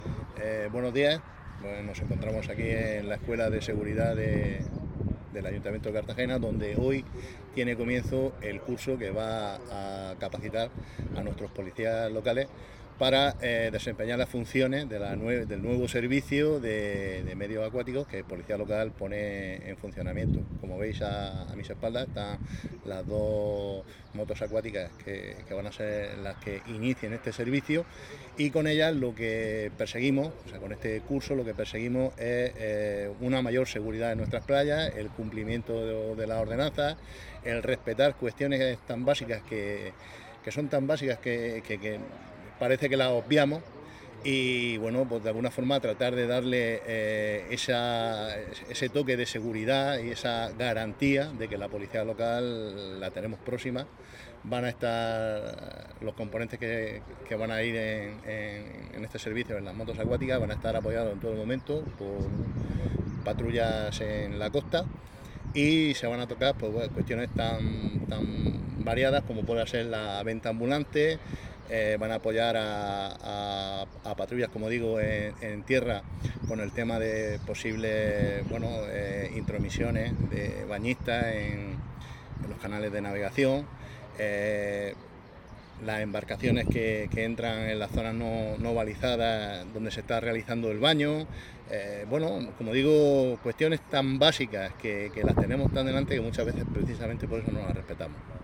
Enlace a Declaraciones de José Ramón Llorca.